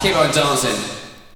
VOX SHORTS-2 0009.wav